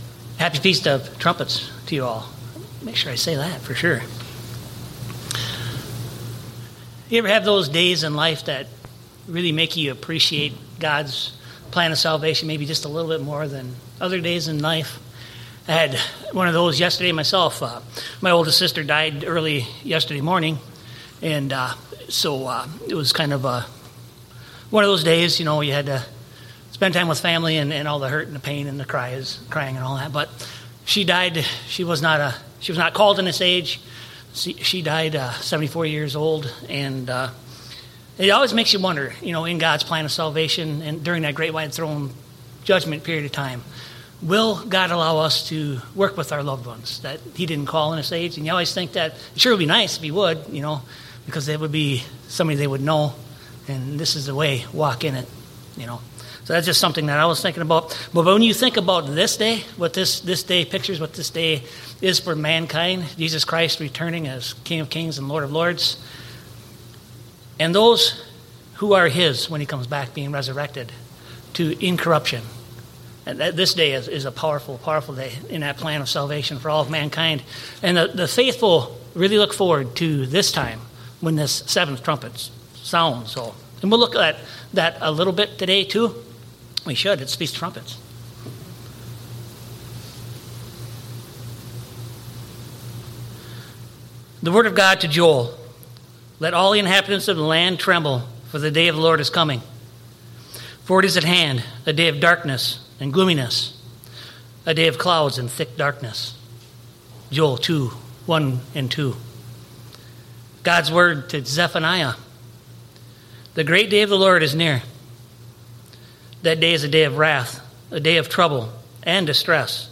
In this Feast of Trumpets message, the speaker reflects on the significance of this holy day and its promise of Christ's return. Drawing from passages in Joel, Zephaniah, Leviticus, and Revelation, the message takes us through the biblical meaning of the seven trumpets, each one heralding events leading to Jesus Christ’s second coming. The speaker emphasizes the trials, warnings, and judgments that will occur as prophesied, but also highlights the tremendous hope for those who remain faithful.
Given in Eau Claire, WI